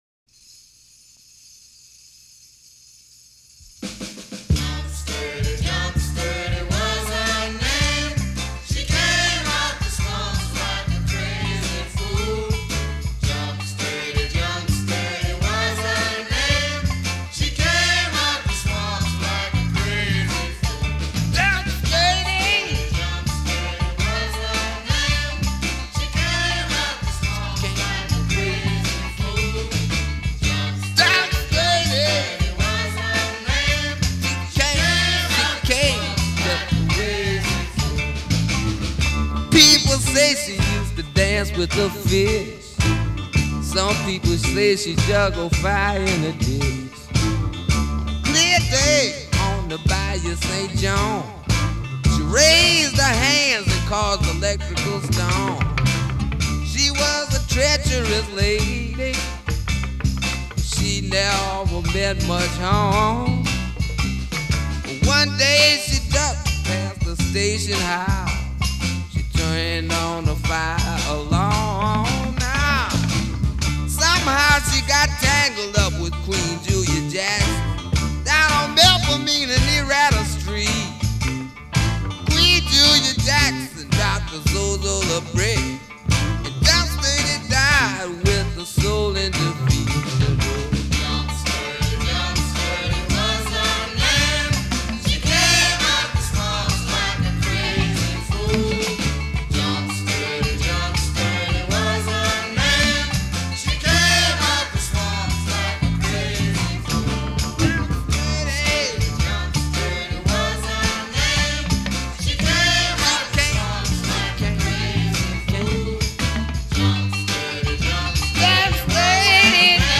Жанр: Rock-n-Blues